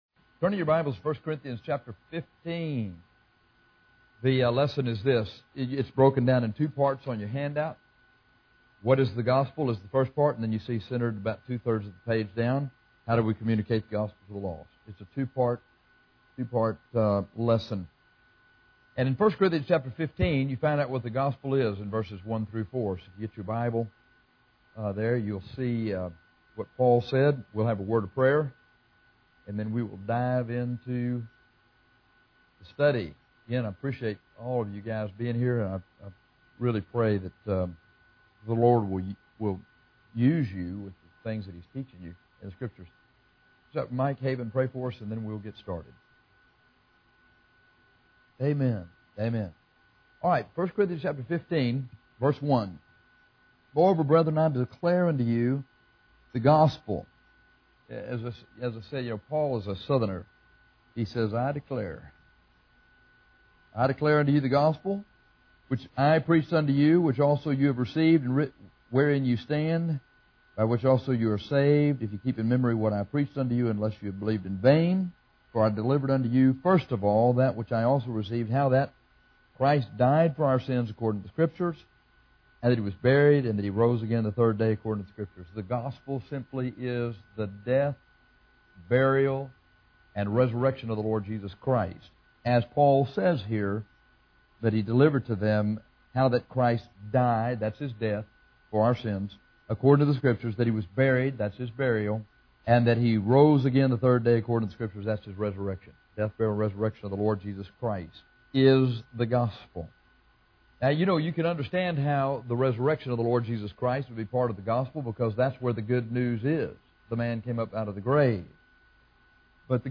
Soul Winning Lesson #3